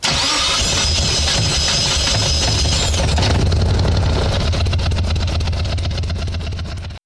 Index of /server/sound/vehicles/lwcars/morgan_3wheeler
startup.wav